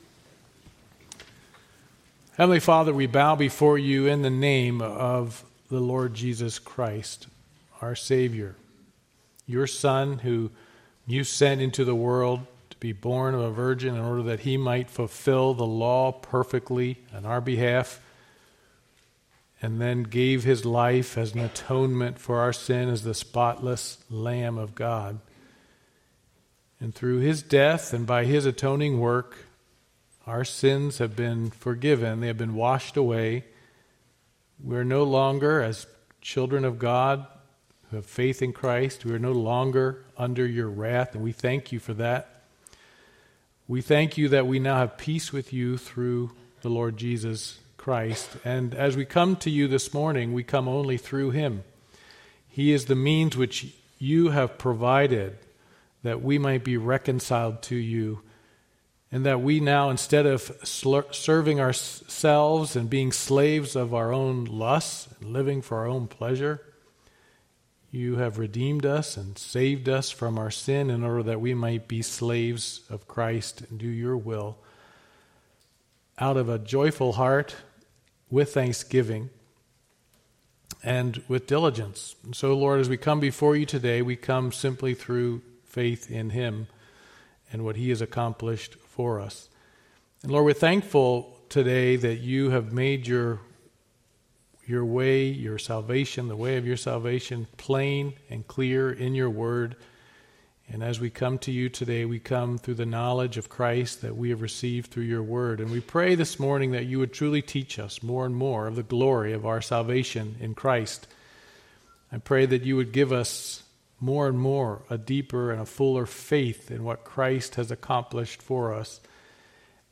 Passage: 2 Peter 1 Service Type: Sunday Morning Worship